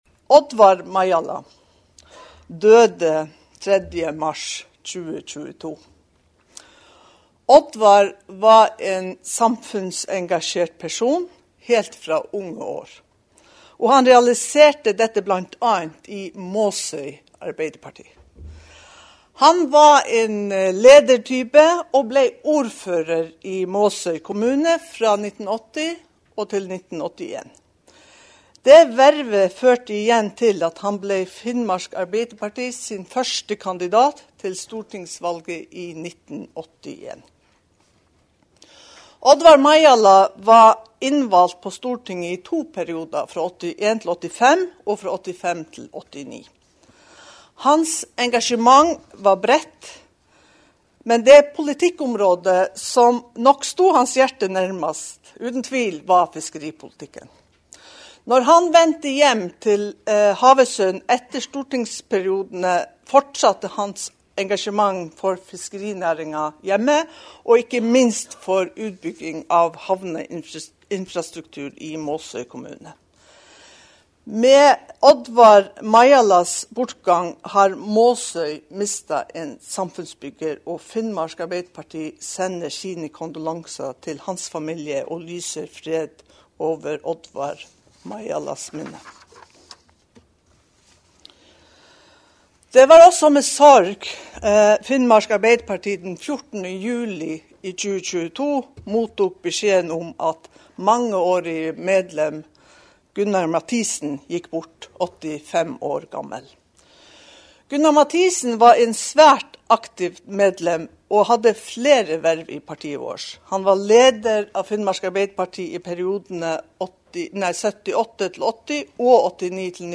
Årsmøte i Finnmark Arbeiderparti, Thon Hotels Kirkenes.